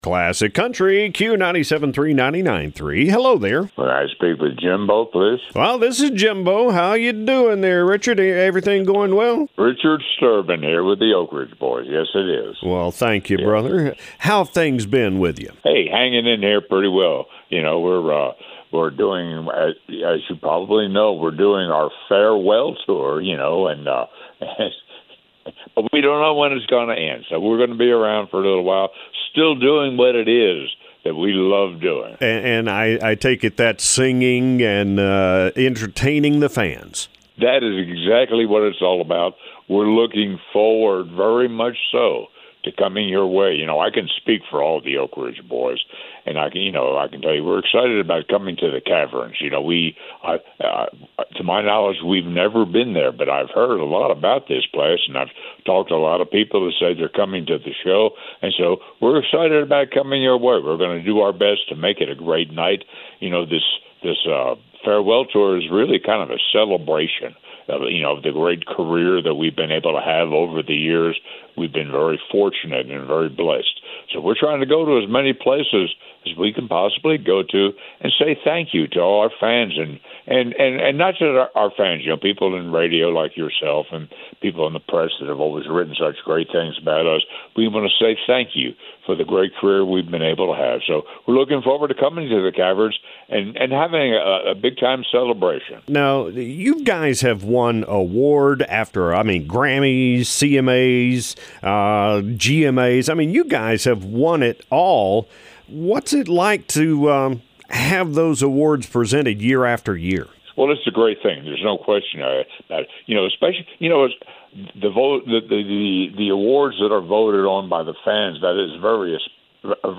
Oak Ridge Boys Coming To The Caverns-Richard Sterban Interview